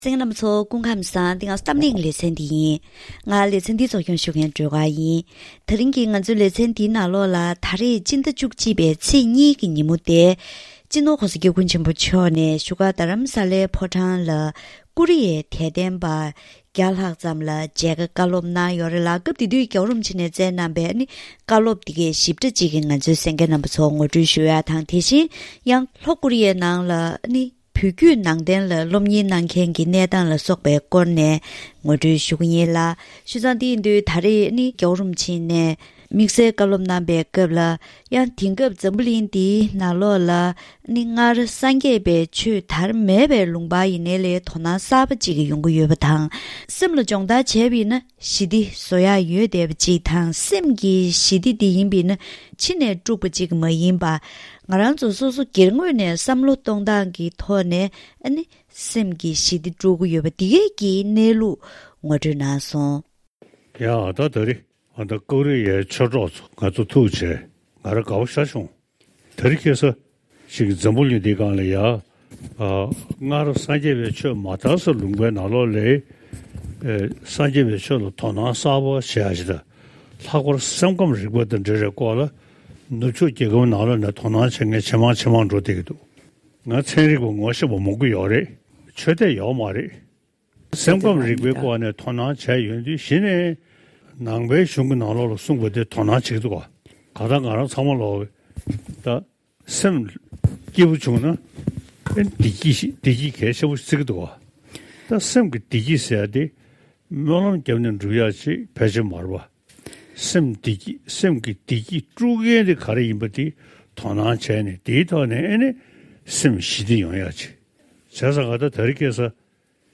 འབྲེལ་ཡོད་ཐོག་ནས་ཀོ་རི་ཡའི་ནང་བོད་བརྒྱུད་ནང་བསྟན་ལ་དོ་སྣང་དང་སློབ་གཉེར་སྐོར་ལ་བཀའ་མོལ་ཞུས་པ་ཞིག་གནས་རོགས་གནང་།